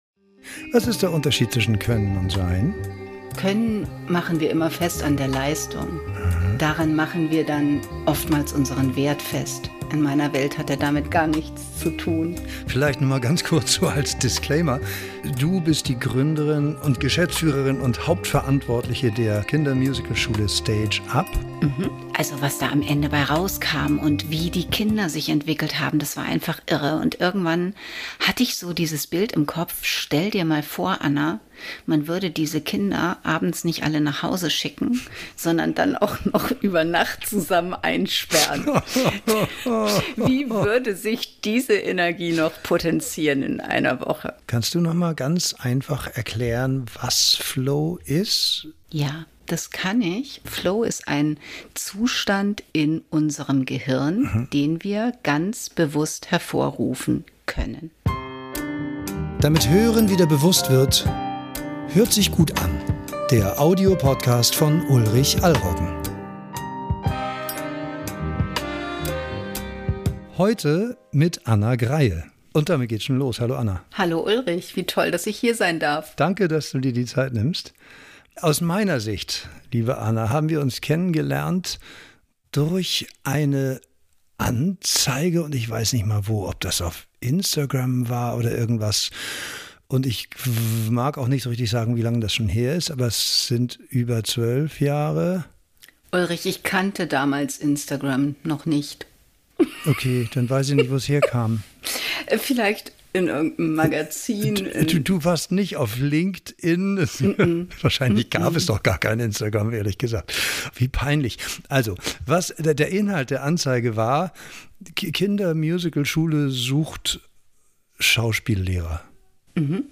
Ein Gespräch, das aus Versehen sehr persönlich geworden ist.